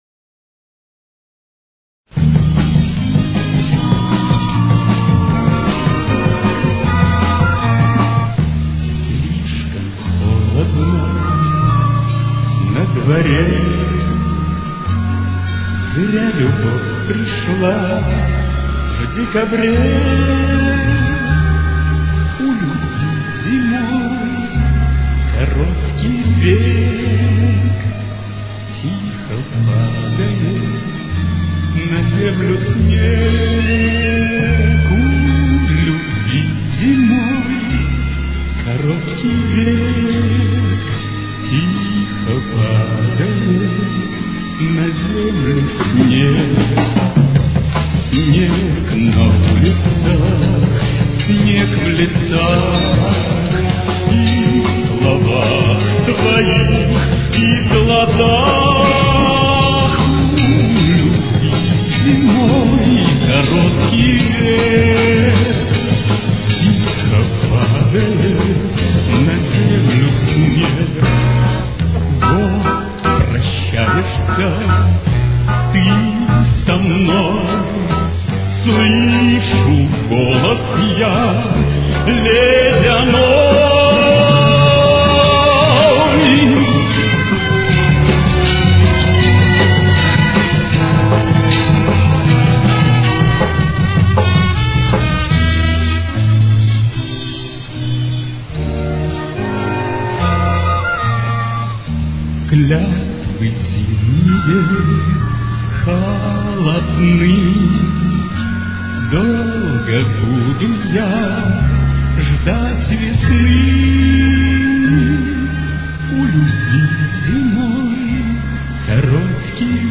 Ми-бемоль минор. Темп: 154.